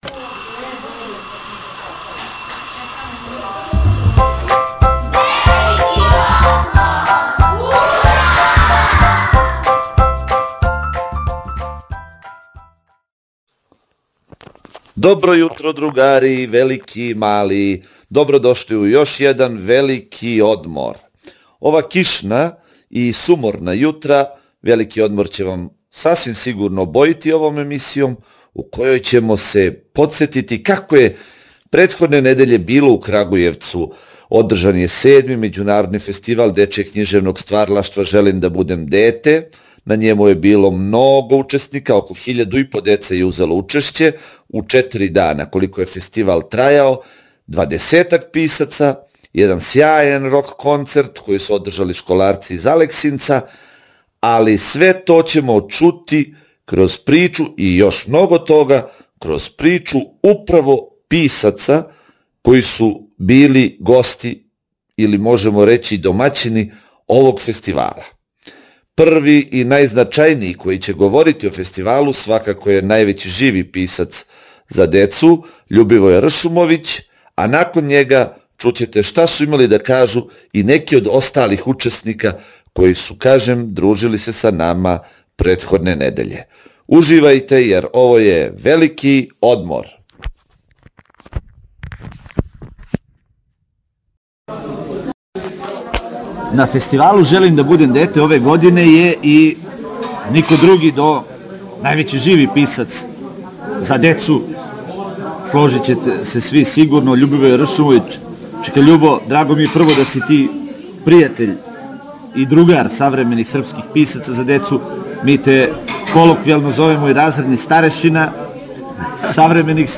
У данашњој емисији слушамо разговор са овим песником и другим учесницима фестивала “Желим да будем дете“.